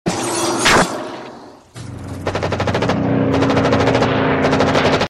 MACHINE GUNS FIRING AND AIRPLANE.mp3
Original creative-commons licensed sounds for DJ's and music producers, recorded with high quality studio microphones.
machine_guns_firing_and_airplane_xvl.ogg